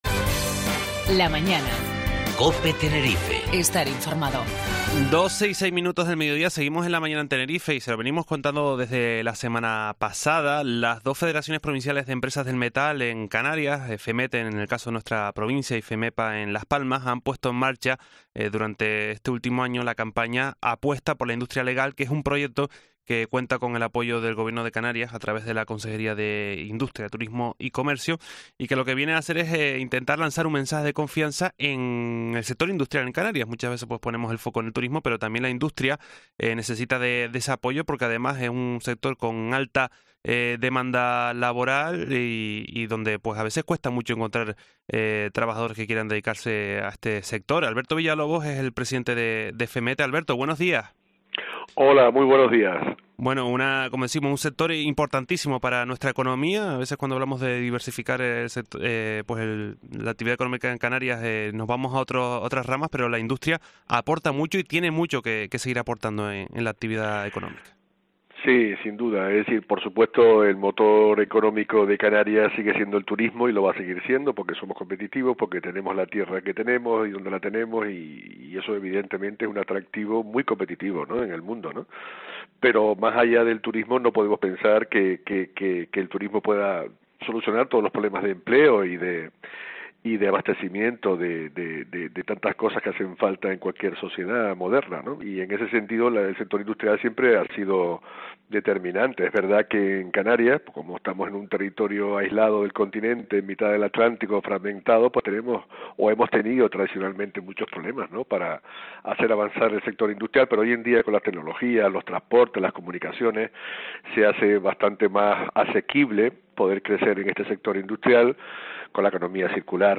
habla del proyecto "Apuesta por la industria legal"